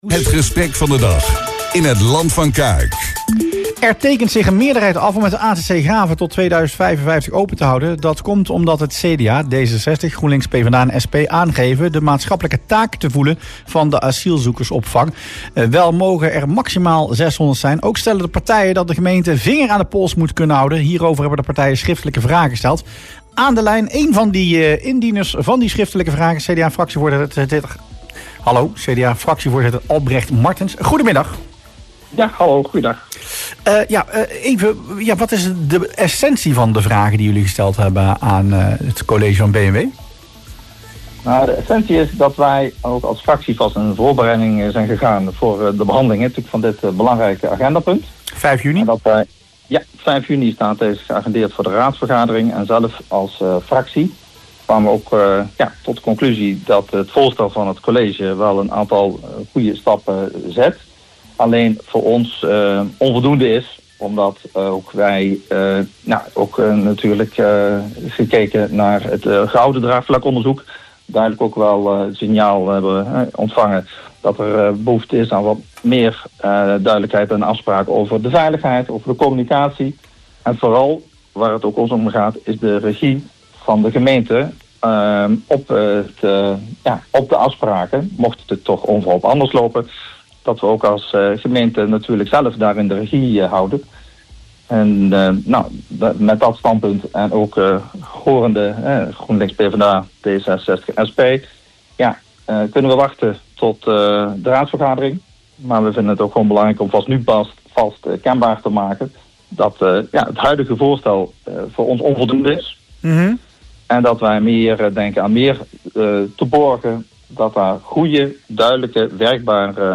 CDA-fractievoorzitter Albrecht Martens in Rustplaats Lokkant